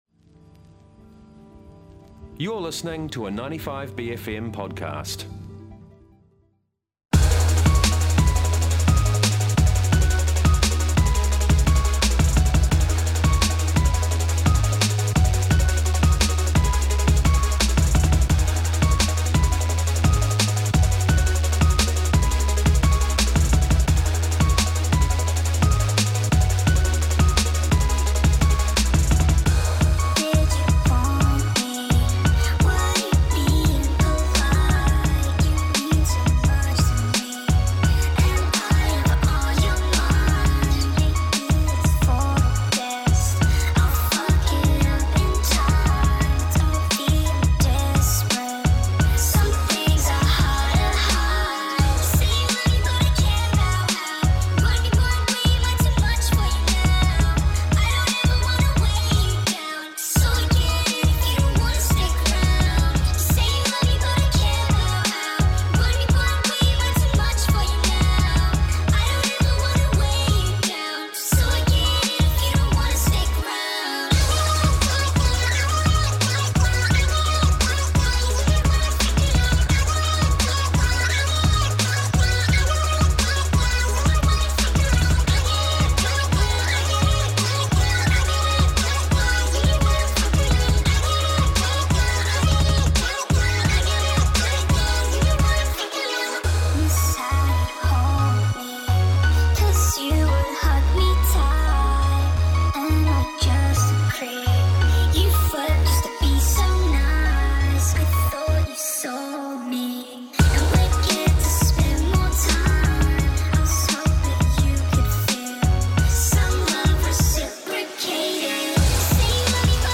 Guest Interviews
A simple feed of all the interviews from our many and varied special bFM Breakfast guests.